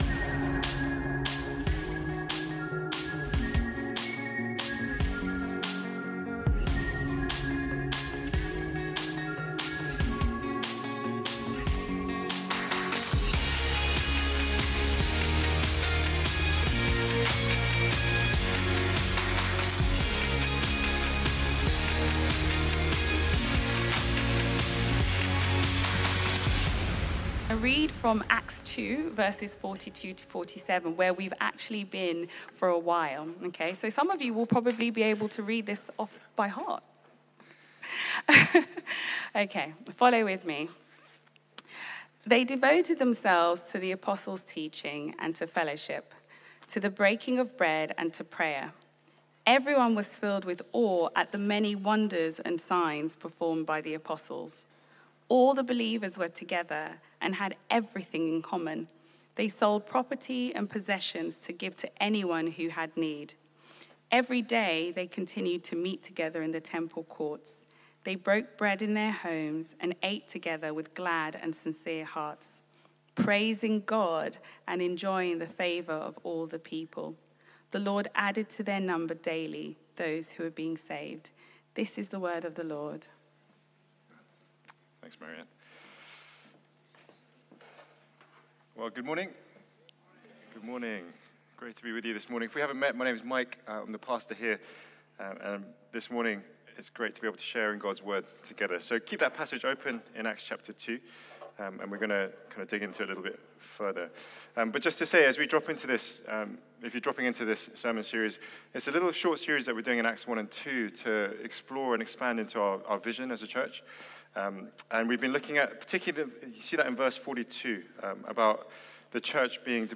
Sunday Sermons - Reality Church London